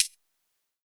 UHH_ElectroHatA_Hit-02.wav